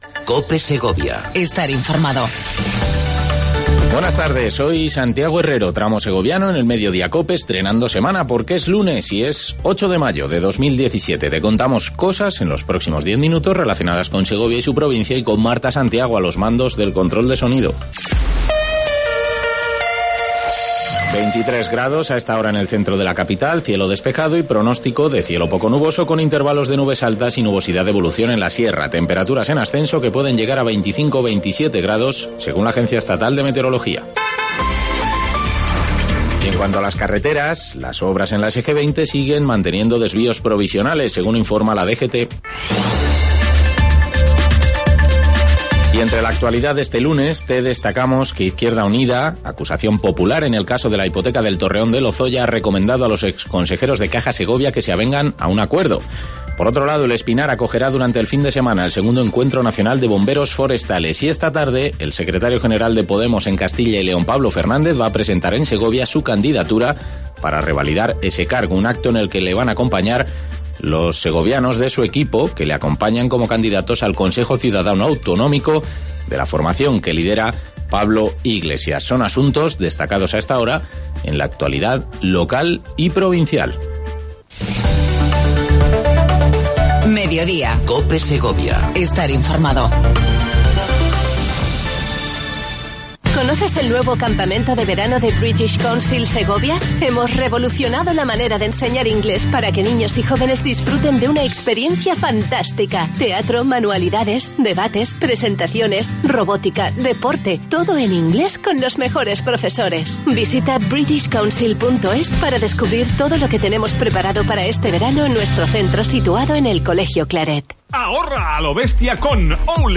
Entrevista con Clara Luquero, alcaldesa de Segovia.